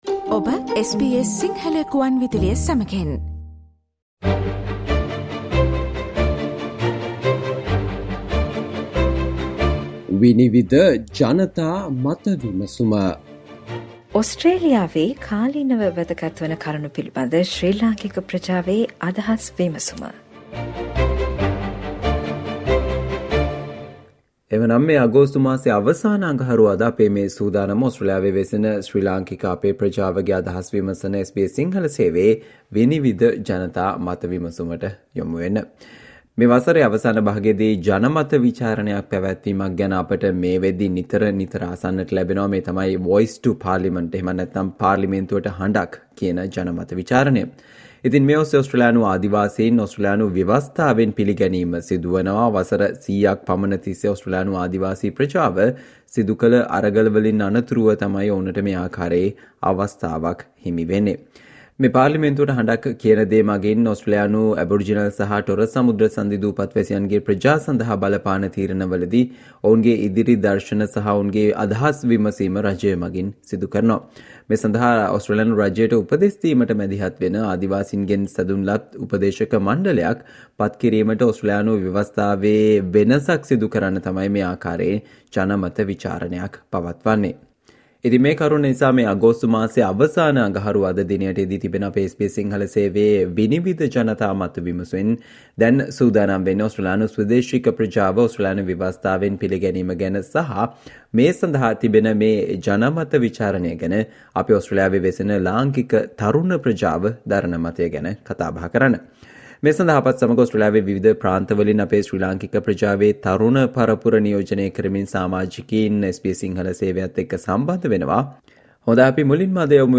SBS සිංහල සේවයේ 'විනිවිද' ජනතා මත විමසුම් වැඩසටහන සෑම මසකම අවසාන අඟහරුවාදා ඔබ වෙත ගෙන එයි.